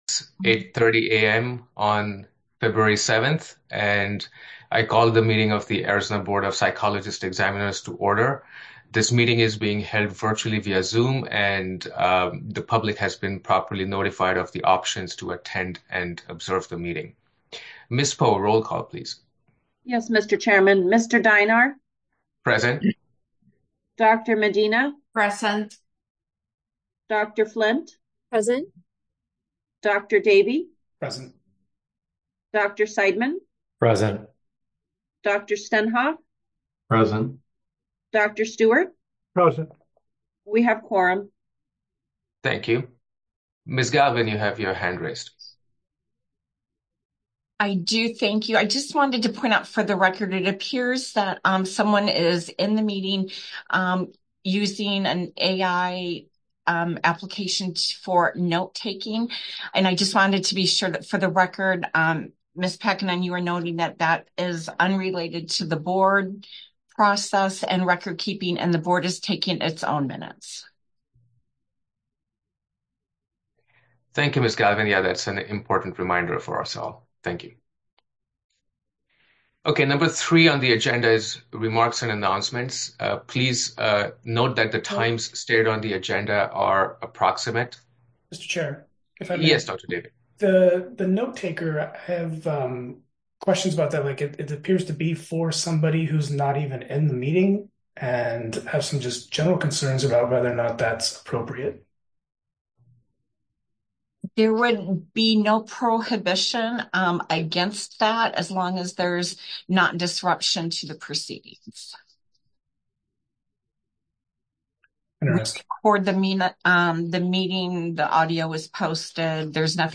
Board Meeting | Board of Psychologist Examiners
Members will participate via Zoom